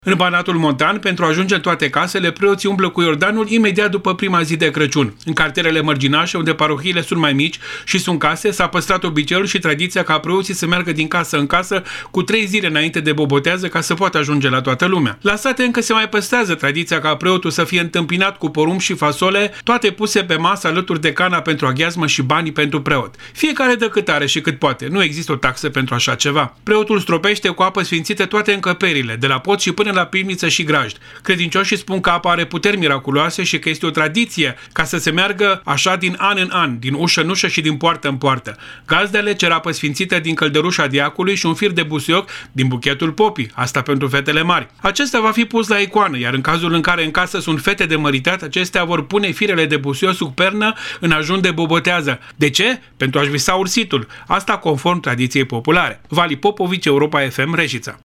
Corespondentul Europa FM